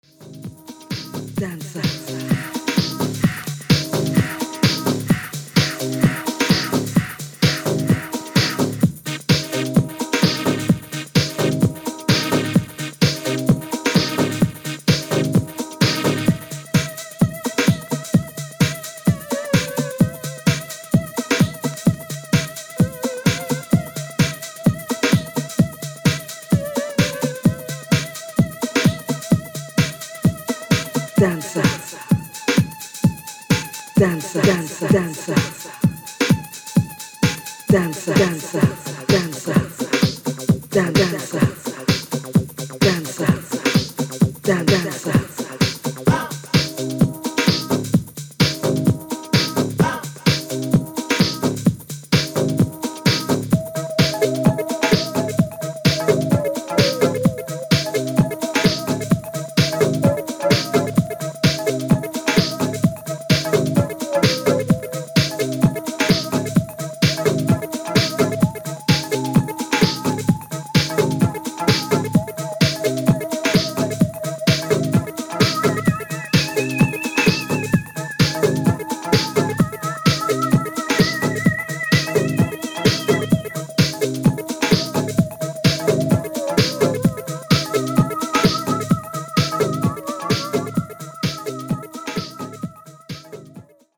SAMPLE音源
当時のNYC・FLOORで必ず使われてました！！！